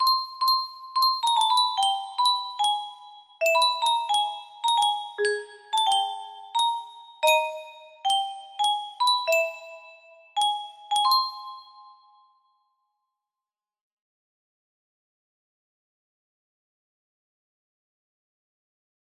Forgetmenot music box melody